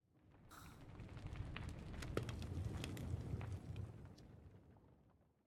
smoker2.ogg